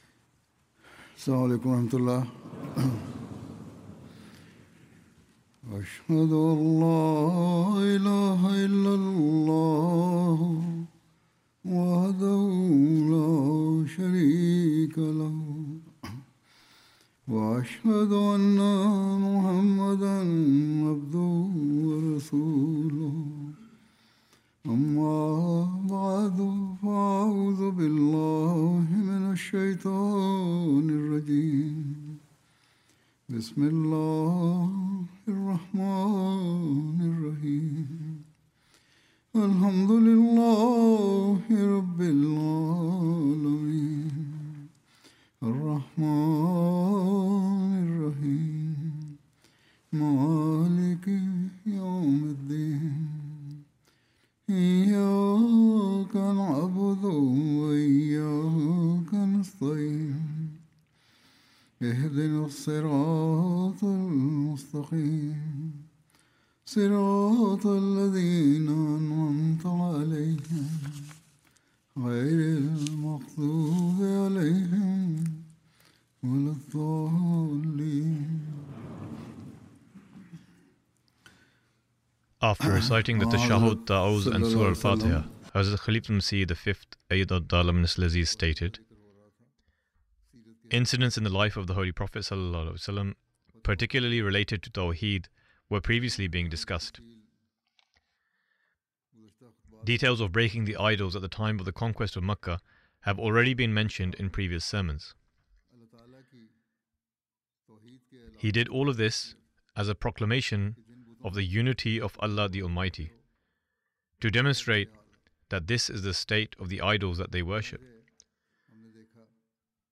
English translation of Friday Sermon (audio)